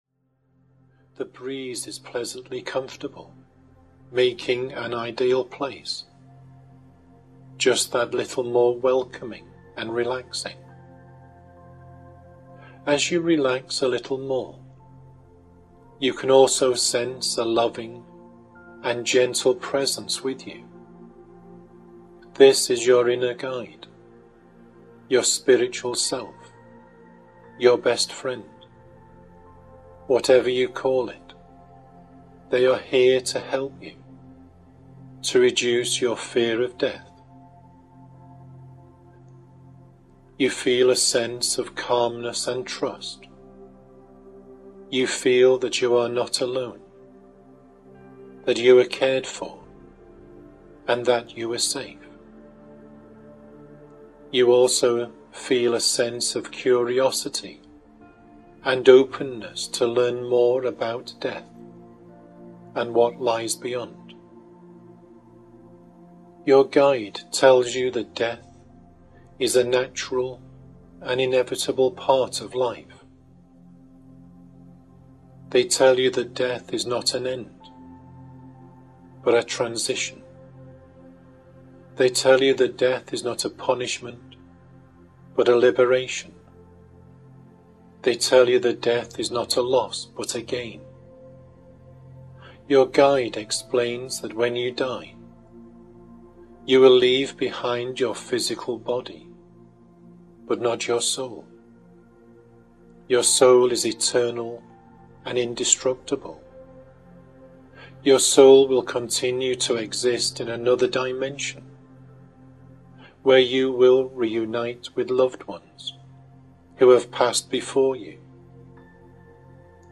Embrace these audio recordings at your own pace, allowing the soothing narratives and gentle guidance to accompany you through the ups and downs.